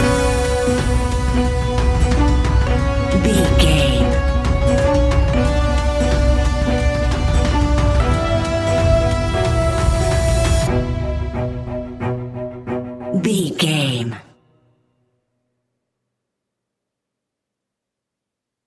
In-crescendo
Thriller
Aeolian/Minor
scary
ominous
dark
suspense
eerie
strings
percussion
piano
drums
ticking
electronic music
Horror Pads
Horror Synths